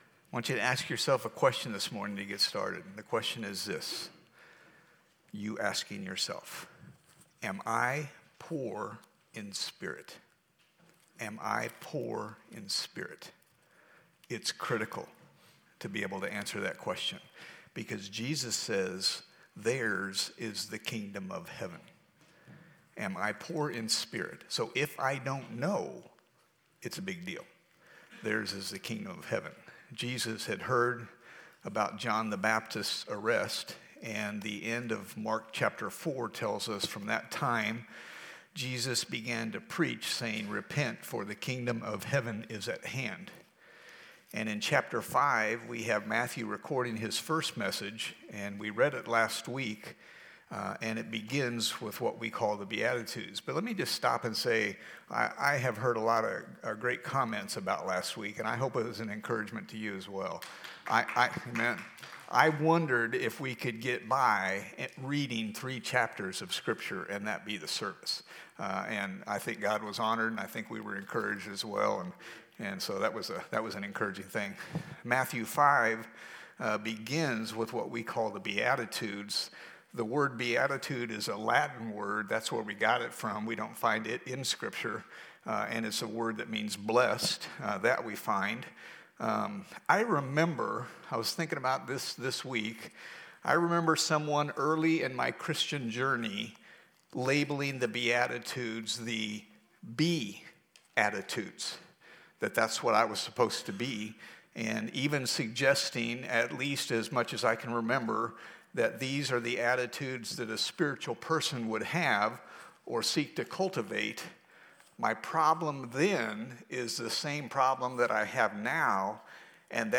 Sermons | Lone Jack Baptist Church